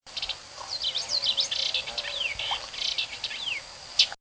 Geothlypis trichas (common yellowthroat)
Here's an uncharacteristic Yellowthroat song - in this sample he got distracted midway through his song and went off on a tangent! At the end of this one you'll also hear the "Chack!" note which they often repeat over and over in the morning while feeding.